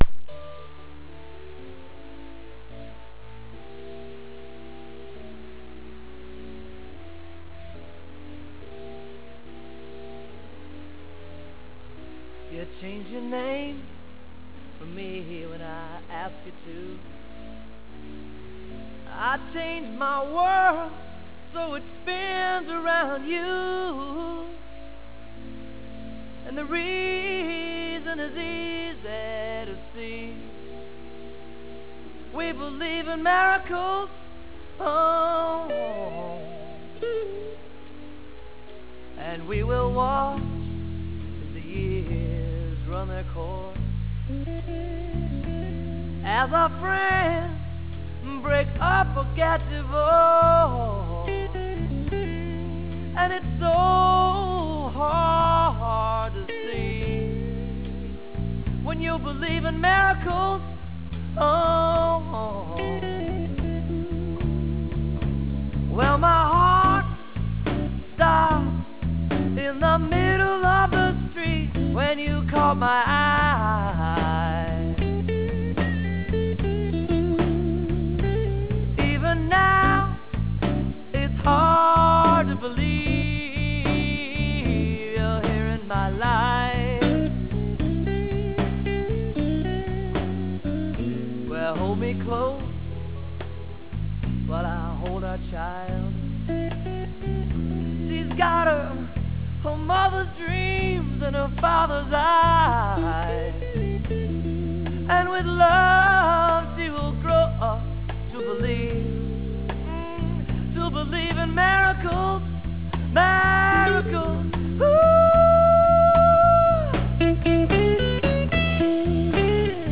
This is a great bluesy number